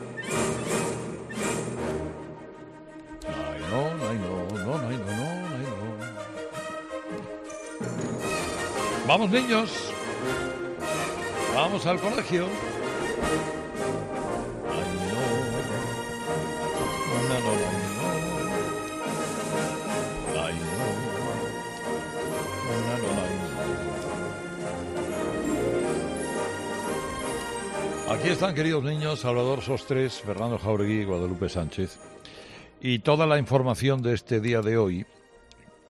Si no sabes muy bien de qué estamos hablando, puedes escuchar el saludo que realizó Herrera este mismo jueves en el siguiente audio, donde, ayer a las 8.30 animaba a los más pequeños a ir al colegio, paso previo a la tertulia de 'Herrera en COPE'.